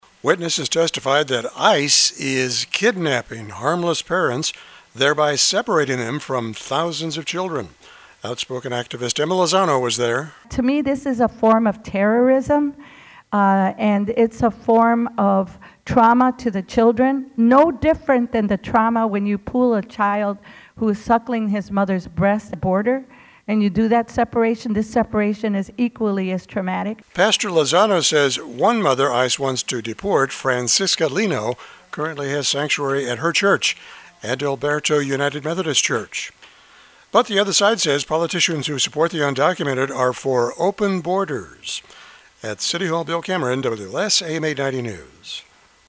(CHICAGO)   A new “Keep Families Together” campaign against separating border families features testimony in city councils, county boards and other legislative bodies around the country.  The City Council Human Relations Committee hosted a hearing on it today at City Hall.